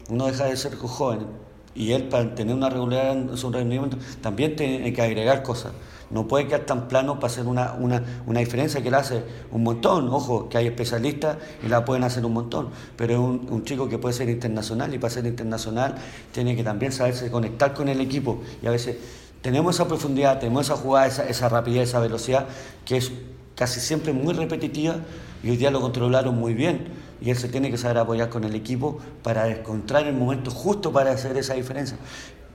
Así lo comentó tras el empate 2 a 2 frente a Cobreloa, momento en que fue consultado por lo que estaba aportando el atacante.